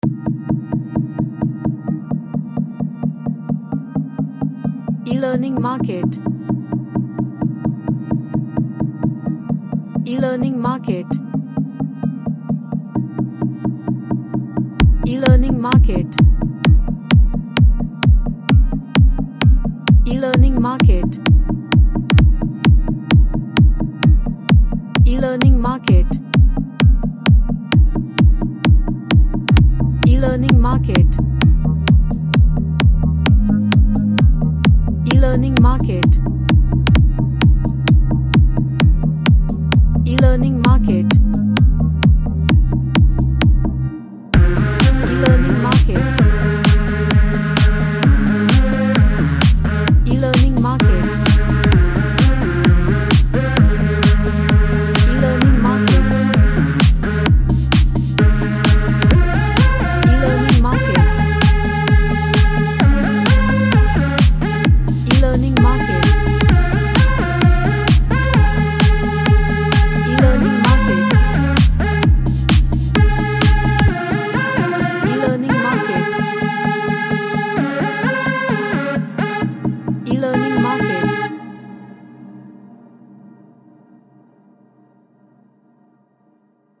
A dance track with house melodics
Chill OutDance